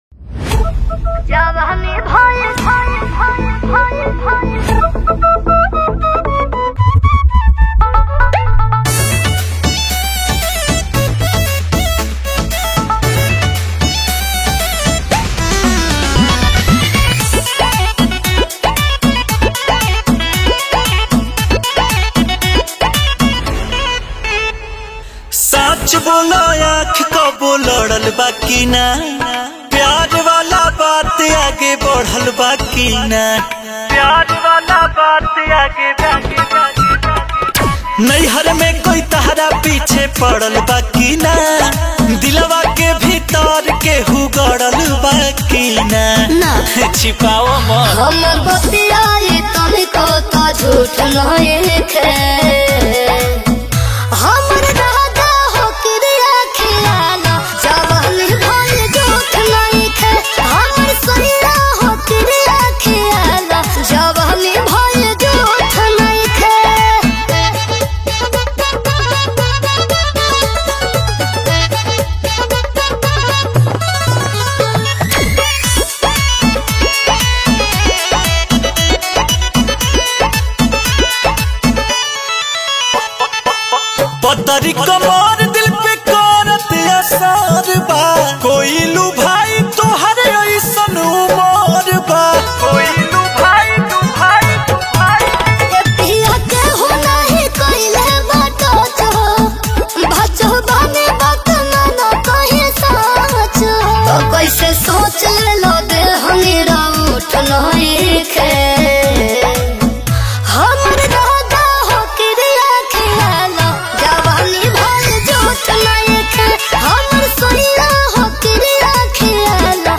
Releted Files Of New Bhojpuri Song 2025 Mp3 Download